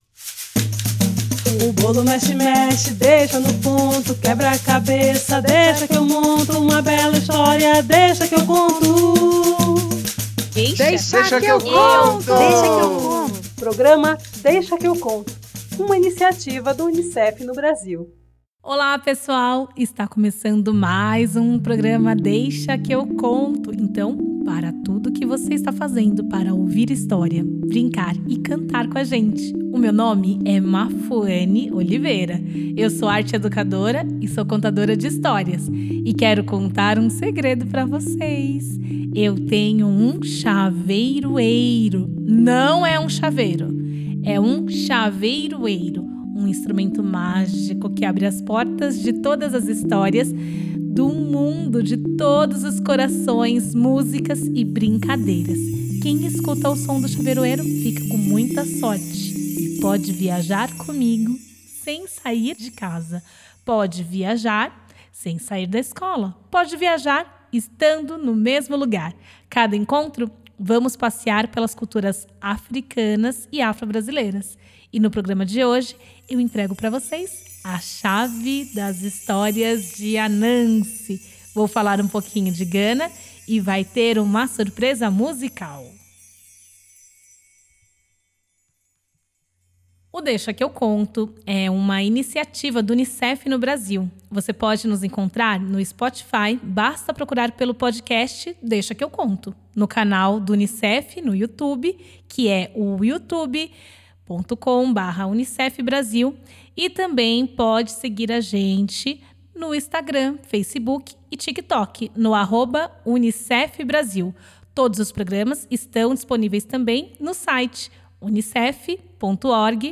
apresenta contos da cultura ashanti, costurando histórias e cantigas com a formação da cultura afro-brasileira. Com o seu Chaveiroeiro, instrumento mágico, abre as portas de Gana e das histórias de Ananse, um personagem que é metade aranha, metade homem, e recebe a missão de recolher toda a sabedoria do mundo.